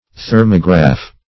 Thermograph \Ther"mo*graph\, n. [Thermo- + -graph.] (Physics)